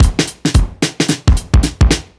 OTG_Kit 5_HeavySwing_110-D.wav